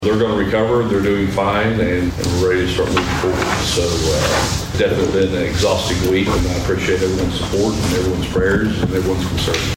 During the new business portion of Monday's Osage County Commissioners meeting, Sheriff Bart Perrier gave an update on the two deputies that were injured in a Pawhuska shooting last week.